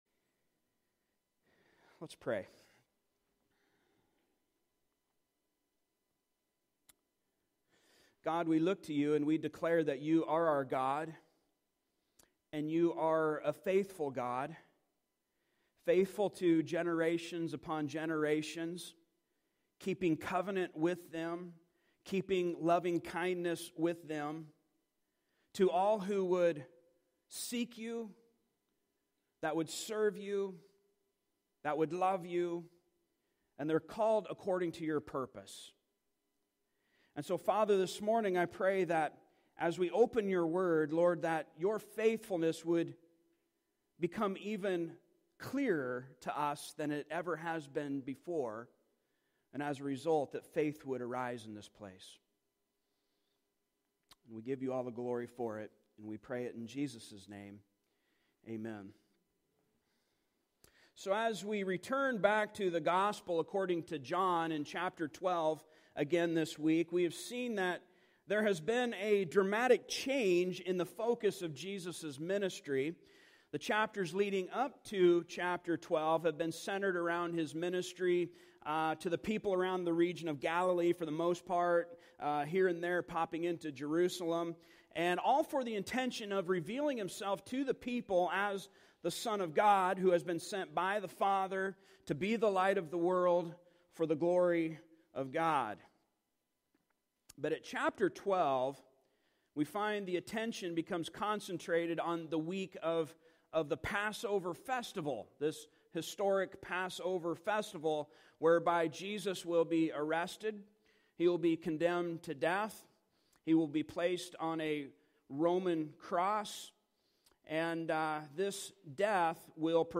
Passage: John 12:19-26 Service Type: Sunday Morning